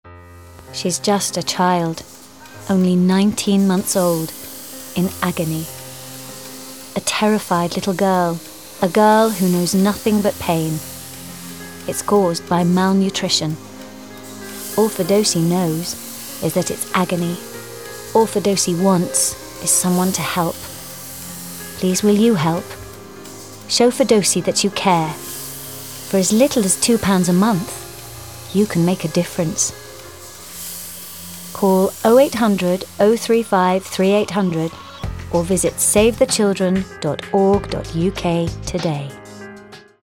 RP. Versatile characters, many accents & standard English. Bright, young, upbeat ads, also a singer.